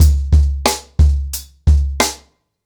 TrackBack-90BPM.41.wav